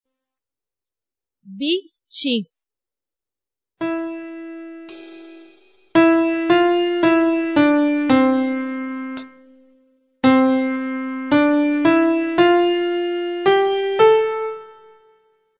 Qualora gli mp3 (di 2ª qualità per non appesantire il sito), di questa pagina, non fossero perfetti nell'ascolto, scriveteci, Vi invieremo sulla vostra casella di posta i file di 1ª qualità, gratuitamente.